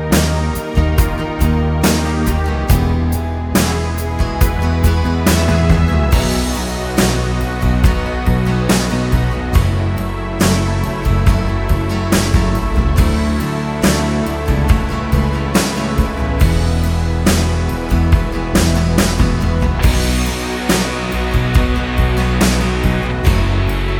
Alternative Ending Pop (1980s) 5:06 Buy £1.50